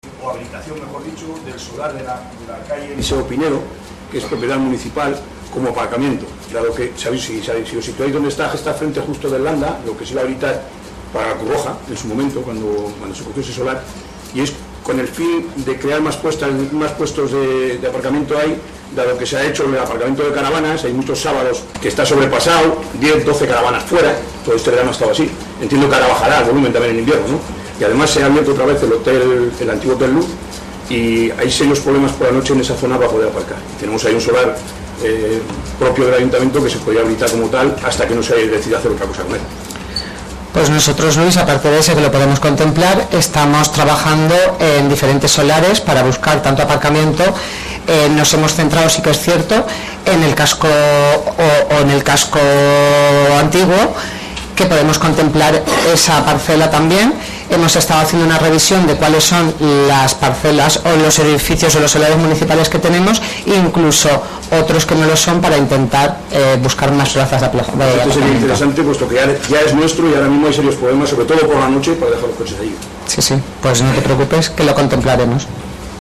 AUDIO. Luis Salazar pide en pleno que se habilite como aparcamiento una parcela municipal junto al Silo